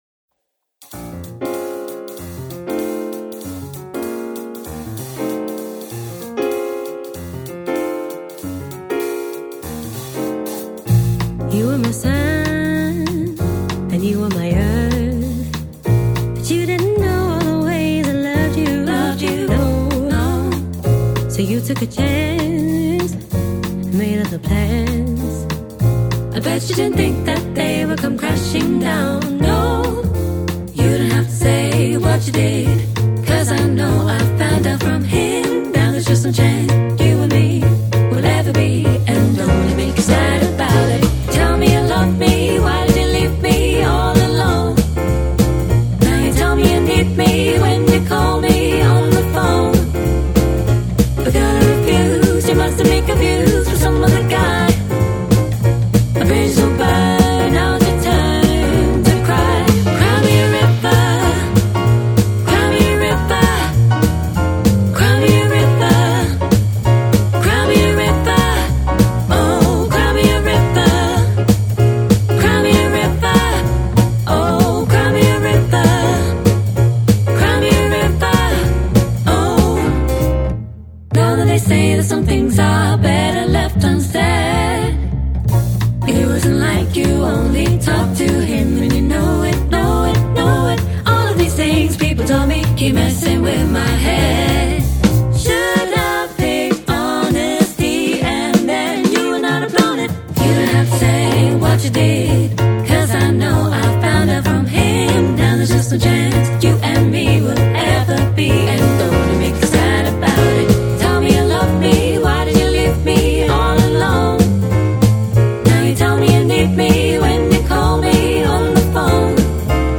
• Unique vintage twists on popular songs
Three Female Vocal Harmony Speakeasy Swing Band for Hire